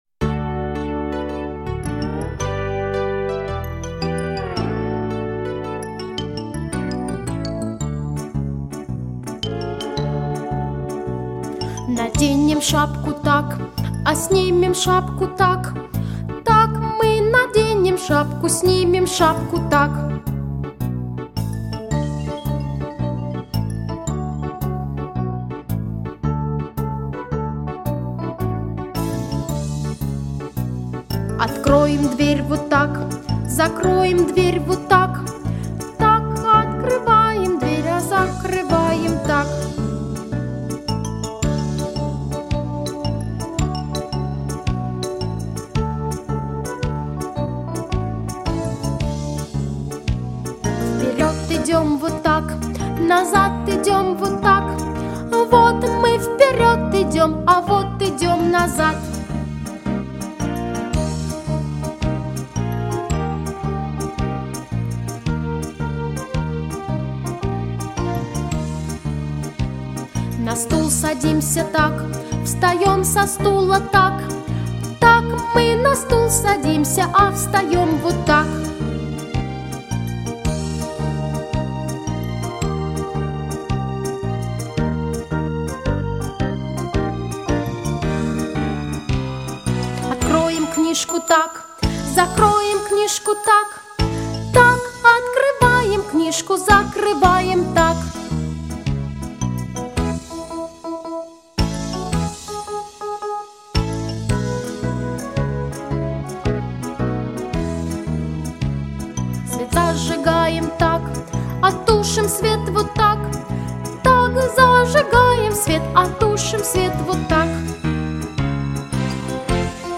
Детский сад